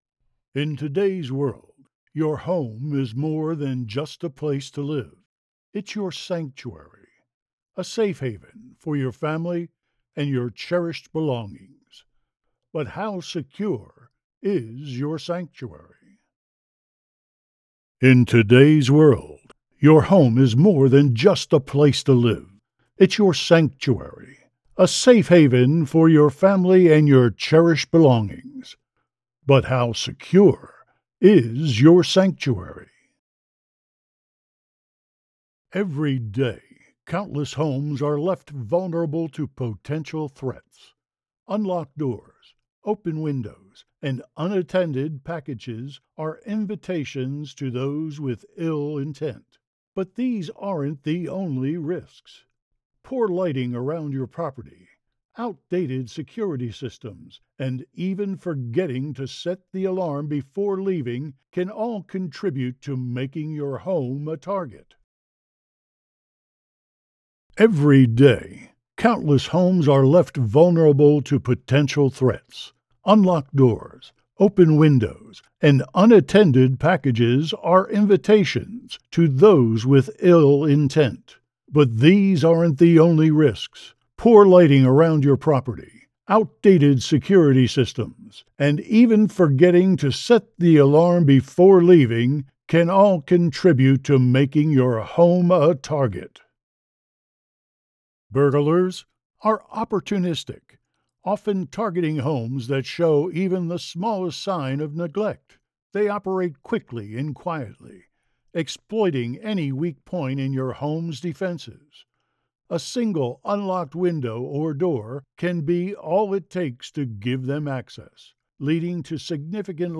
Mid Atlantic, Southern
Middle Aged
Senior
So, here I am with a MacBook Pro computer, a Neumann TLM103 microphone and the equipment to tie everything together providing broadcast quality recordings for the masses.